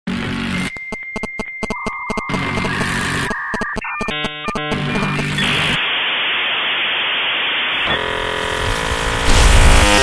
Звук коллапса техногенной аномалии